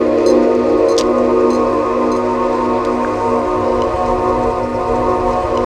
04 S.C.U.B.A F#.wav